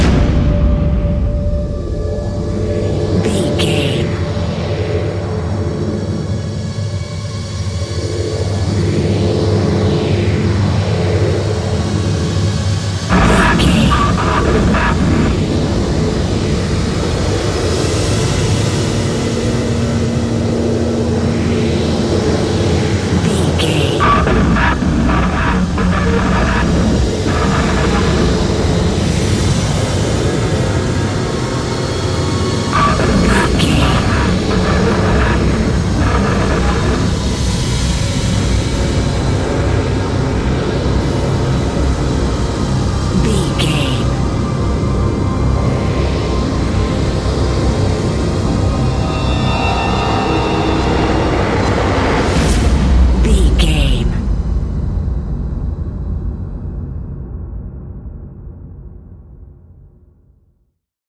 Ionian/Major
F♯
industrial
dark ambient
EBM
drone
synths
Krautrock